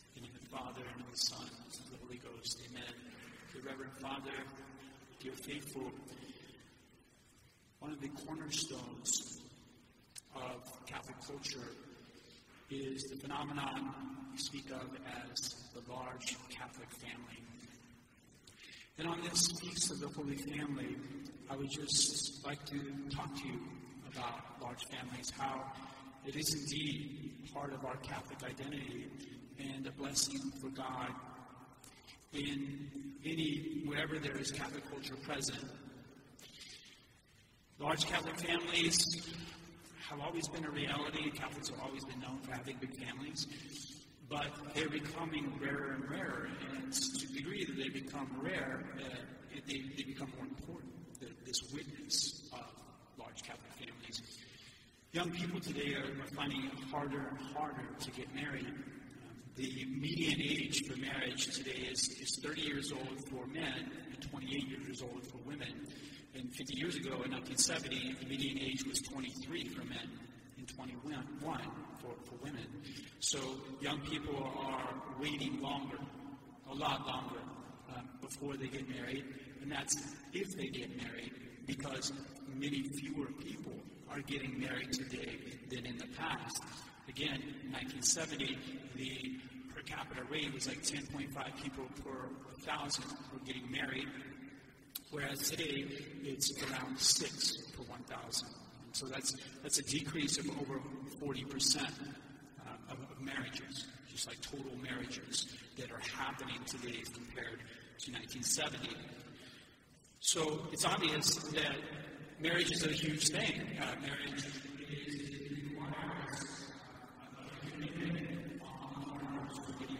The audio file for this sermon has very bad audio from 2:00=15:40.